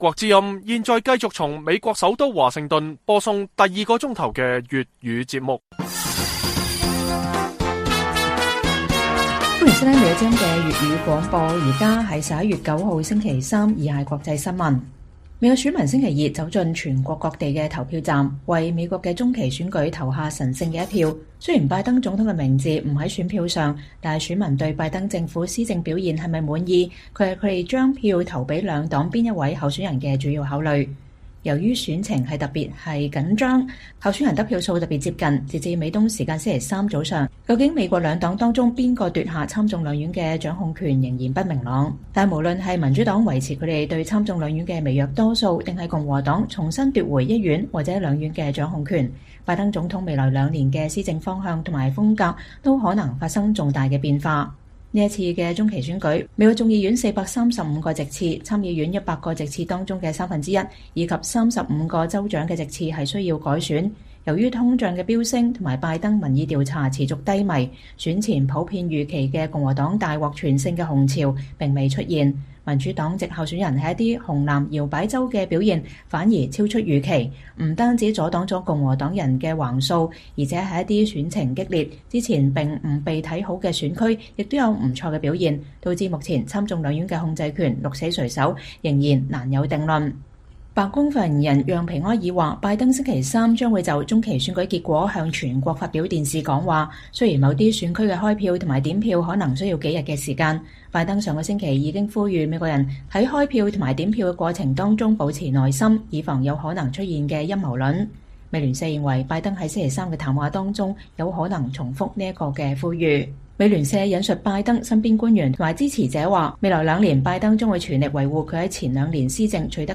粵語新聞 晚上10-11點: 美國中期選舉兩黨各有斬獲，誰掌控國會仍不明朗，但拜登未來施政將會有變化